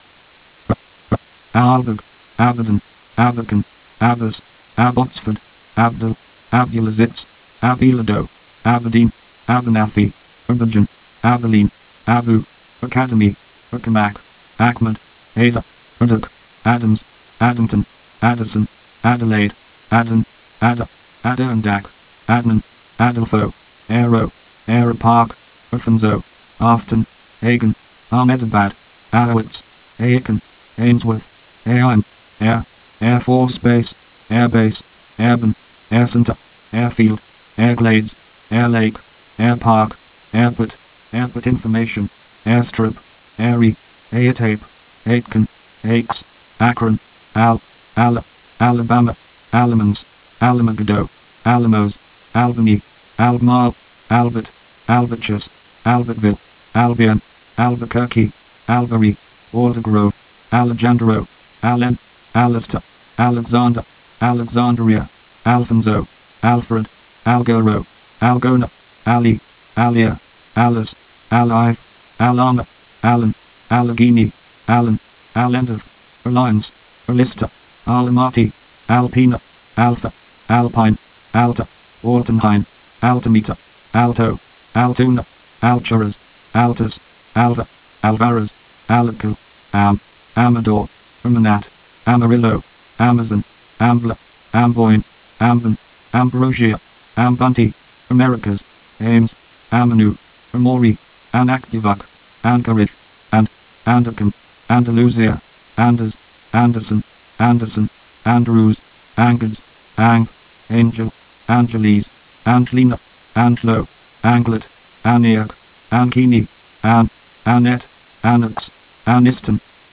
New ATIS speech files